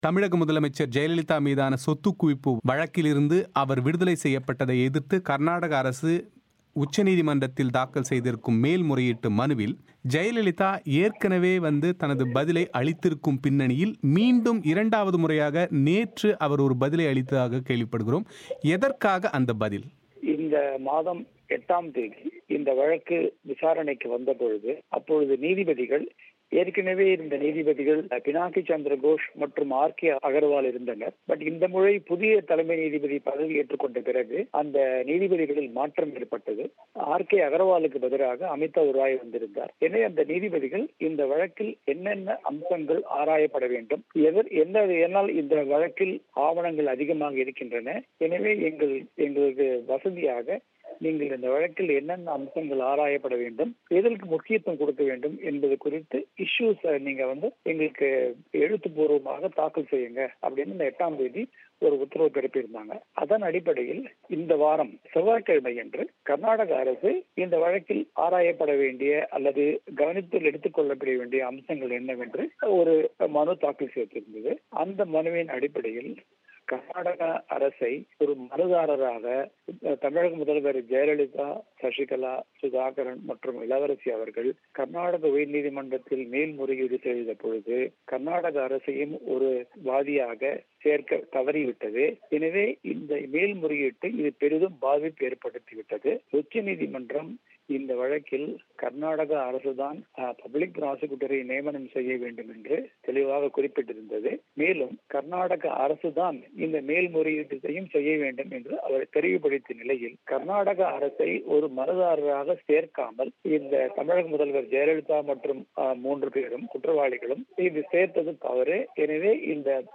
அவரது பேட்டியின் விரிவான ஒலி வடிவத்தை நேயர்கள் இங்கே கேட்கலாம்.